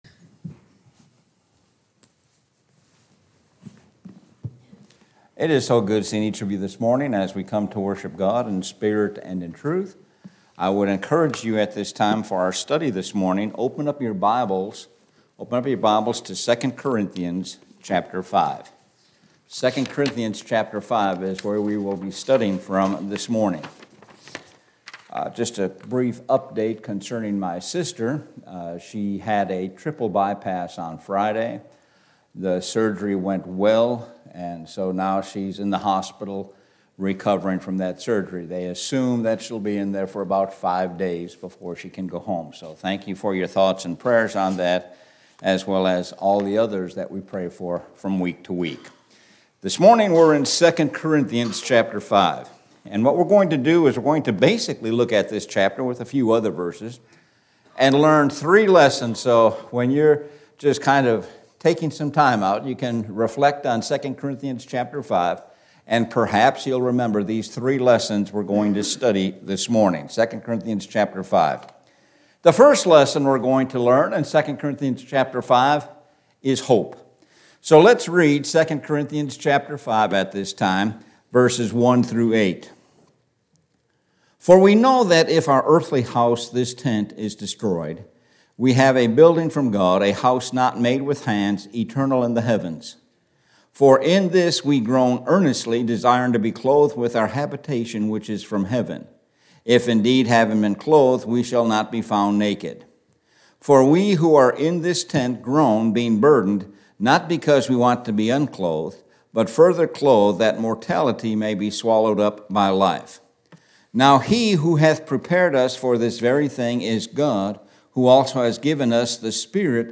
Sun AM Sermon – Hope 2 Corithians 5